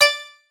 bink.mp3